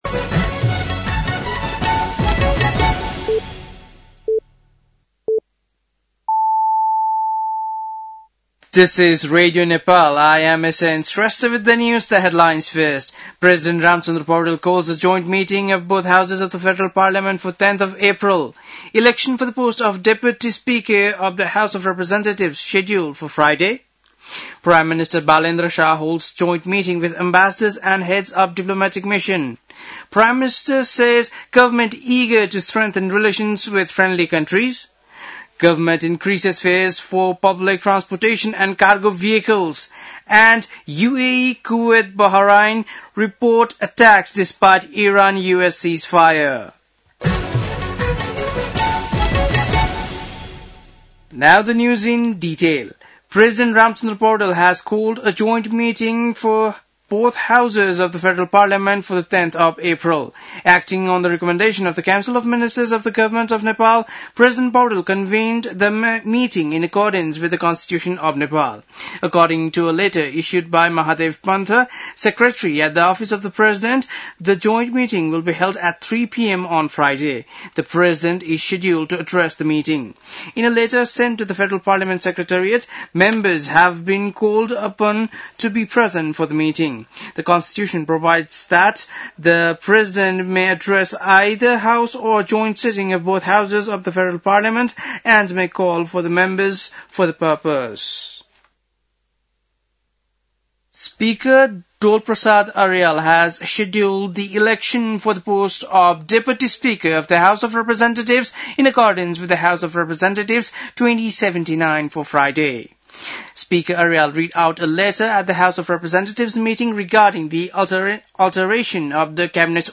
बेलुकी ८ बजेको अङ्ग्रेजी समाचार : २५ चैत , २०८२